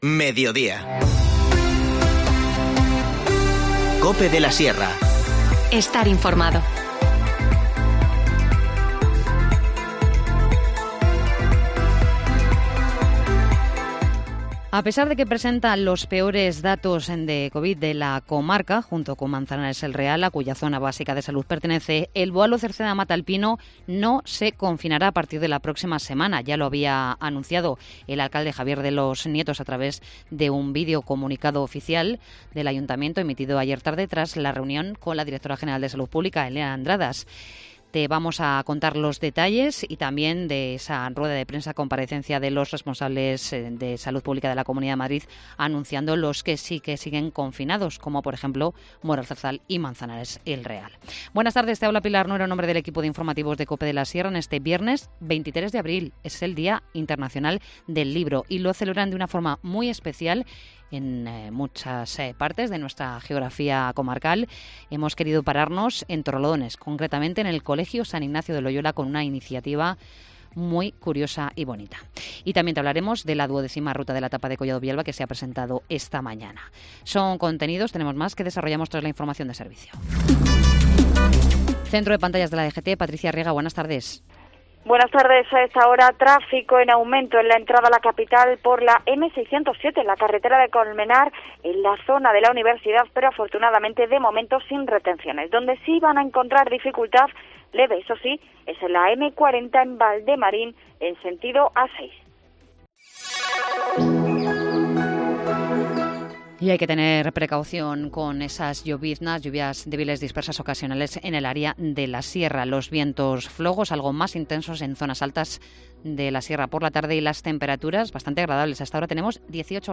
Informativo Mediodía 23 abril